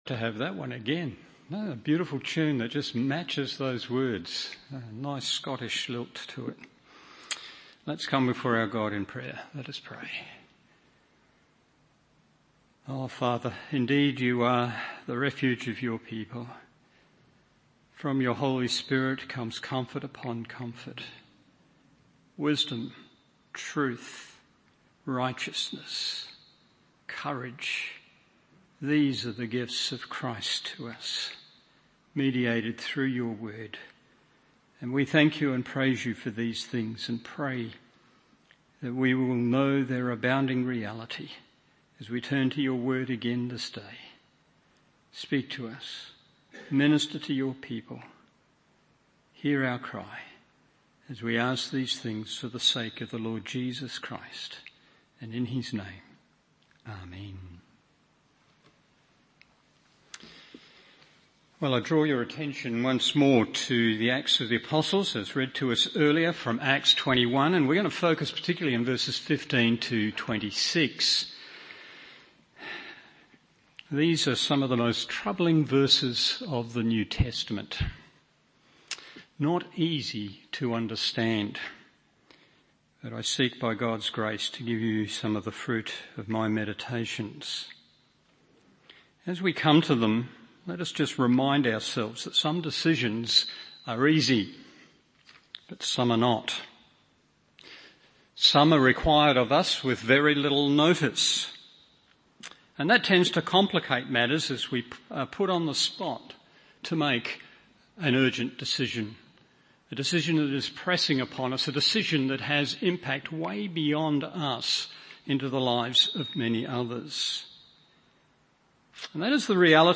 Evening Service Acts 21:15-26 1. A Cause for Joy 2. A Concern over Tension 3. A Course for Alleviation…